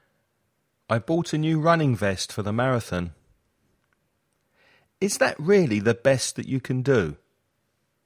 Pronunciación
/b/ - best          /v/ - vest